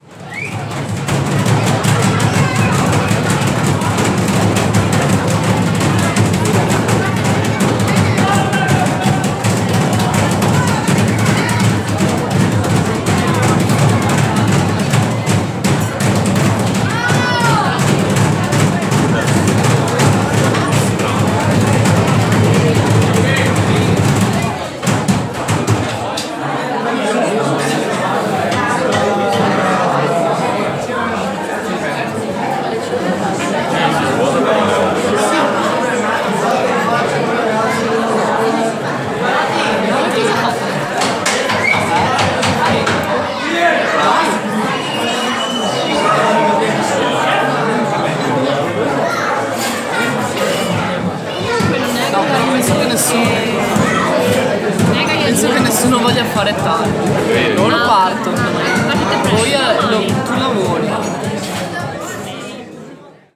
A raucous prosecco festival in Col San Martino, Italy.